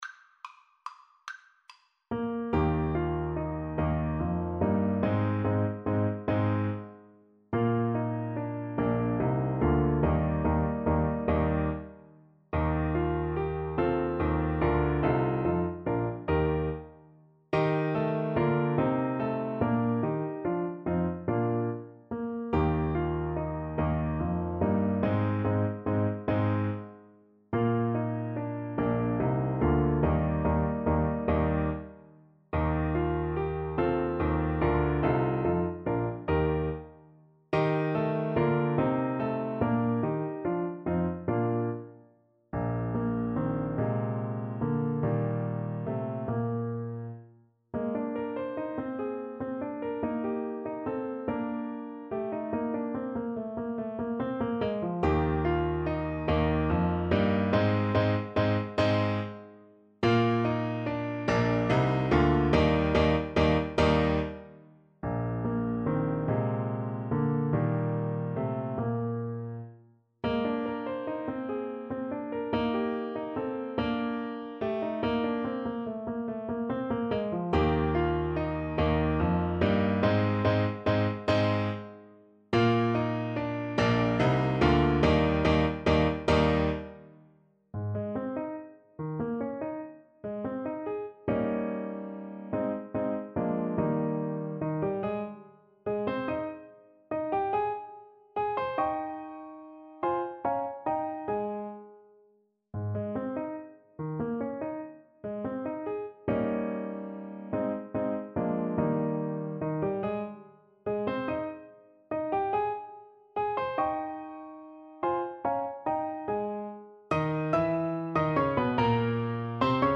3/4 (View more 3/4 Music)
Moderato =c.144
Classical (View more Classical Trumpet Music)